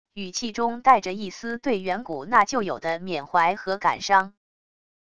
语气中带着一丝对远古那旧友的缅怀和感伤wav音频